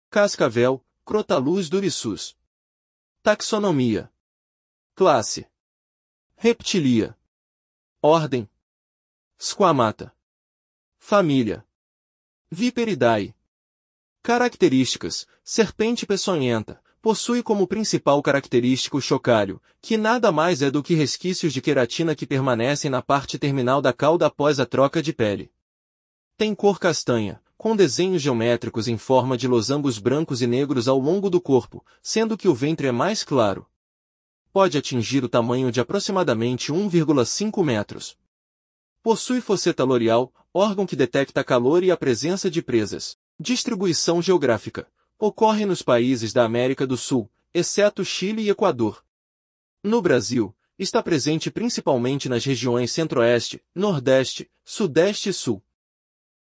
Cascavel (Crotalus durissus)
Quando irritada, emite som característico a partir do chocalho.